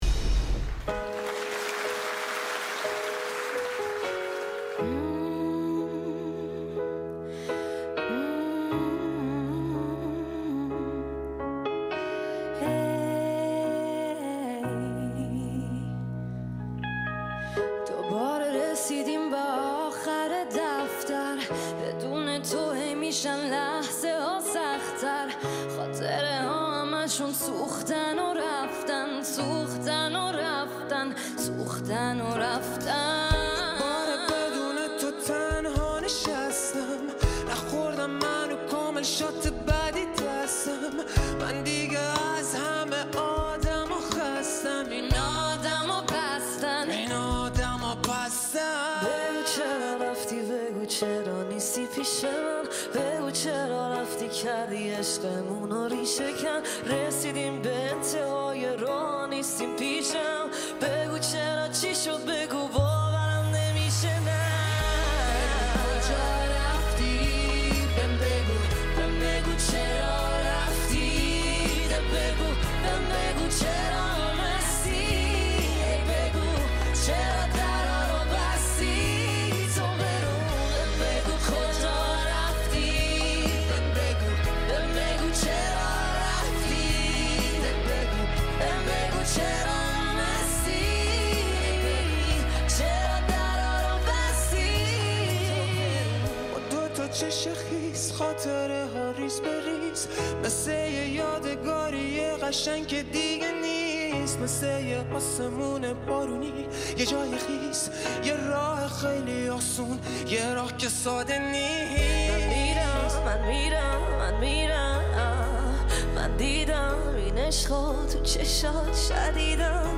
اجرای سه نفره
آهنگ پاپ